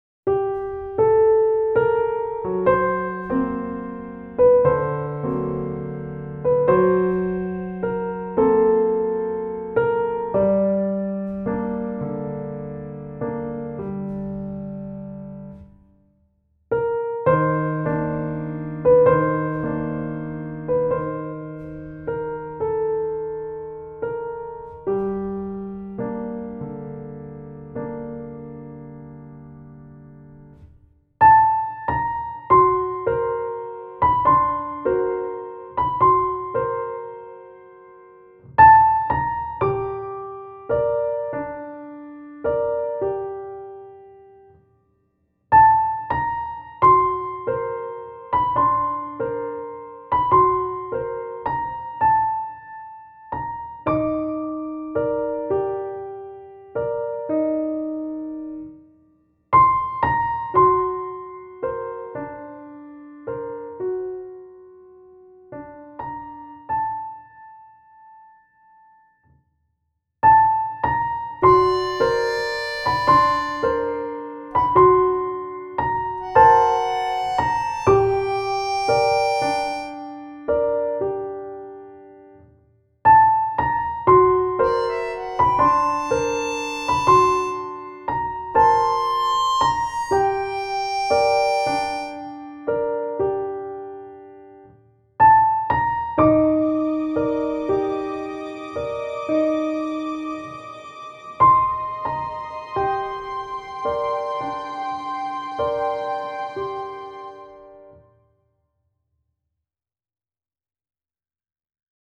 Composed in the style of French impressionism